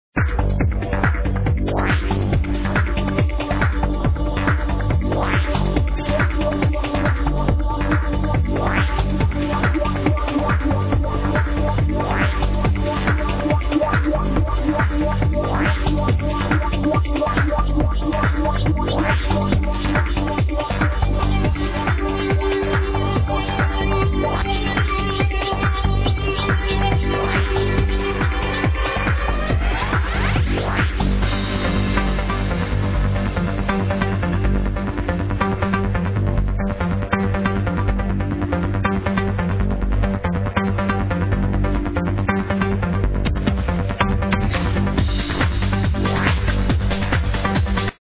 Epic tune